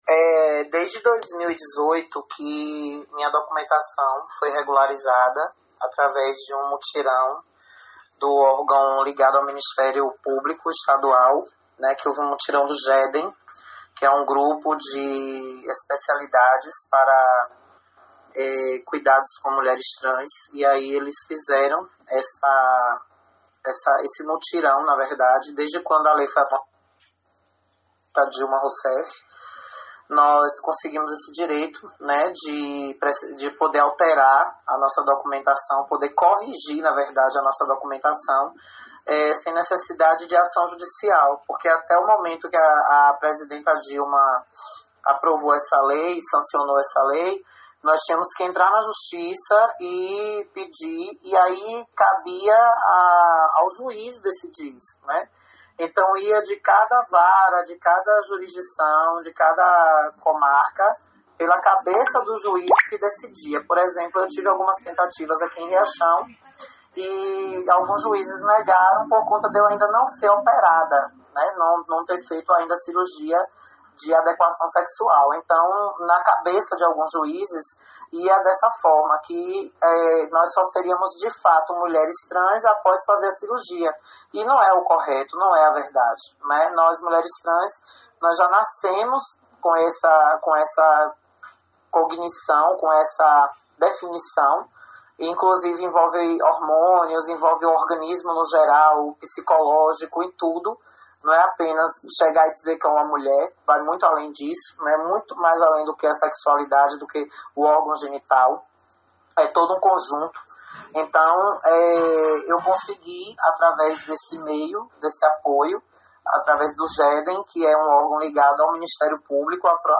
Ela conversou com a reportagem do CN e destacamos alguns pontos de entrevista.